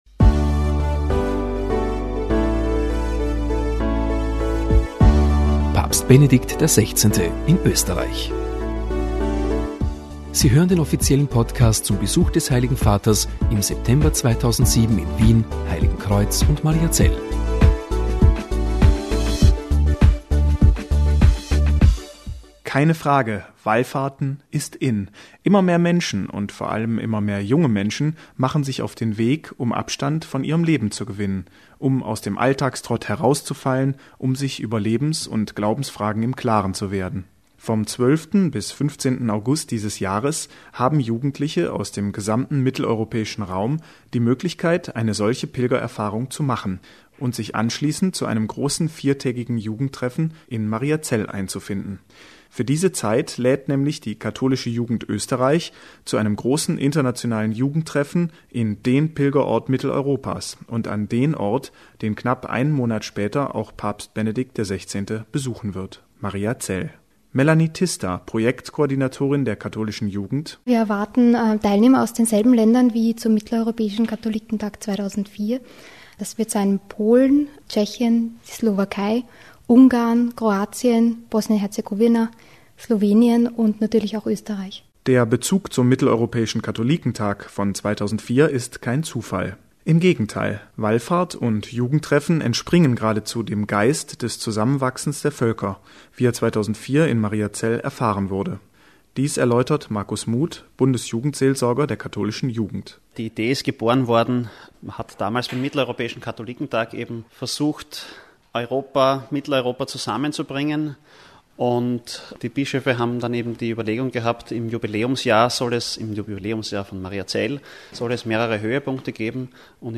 Mitschnitte der Reden sowie Podcasts zum Besuch von Papst Benedikt XVI. 2007 in Österreich